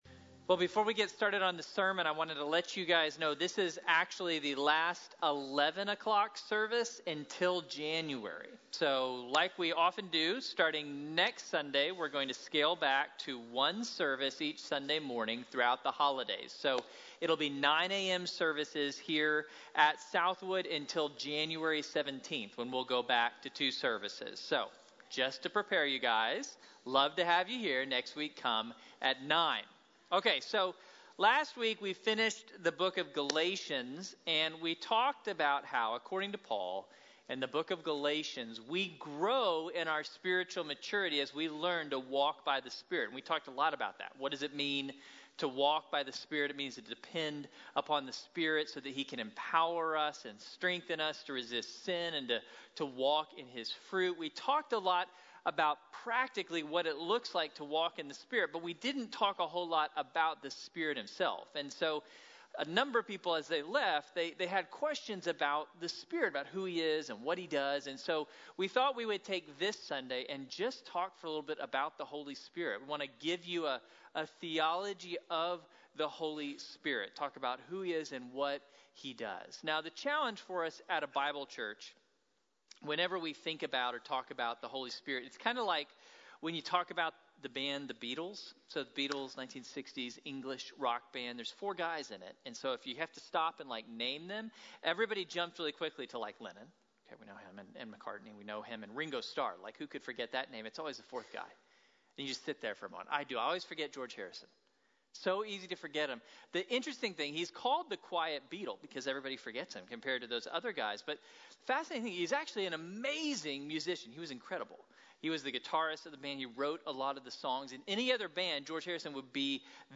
Getting to know the Spirit | Sermon | Grace Bible Church